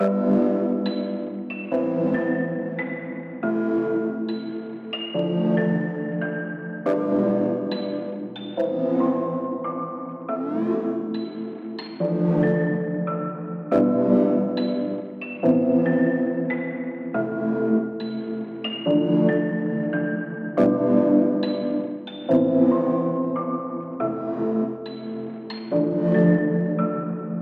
冰冷的环境Lo Fi Trap Loop
描述：冰冷的空间感环境循环。是Lofi / Trap / RnB的理想选择。
标签： 140 bpm Trap Loops Piano Loops 4.61 MB wav Key : Unknown
声道立体声